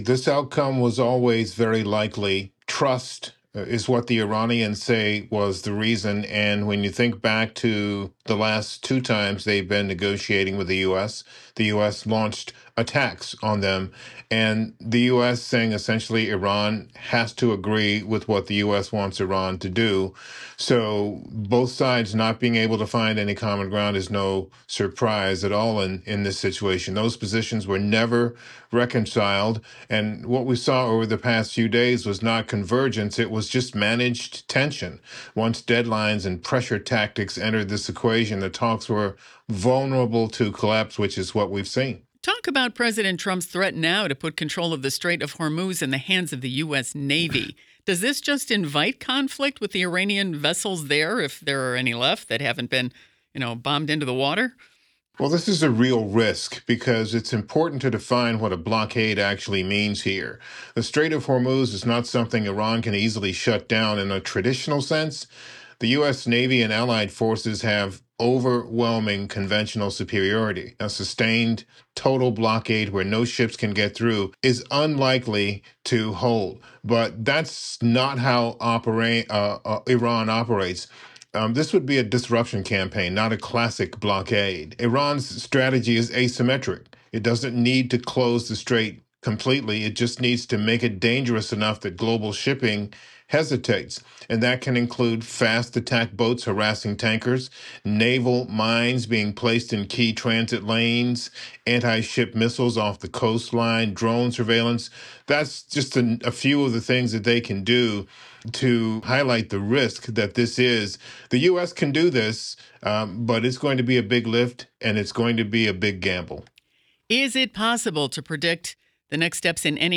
The interview was lightly edited for clarity.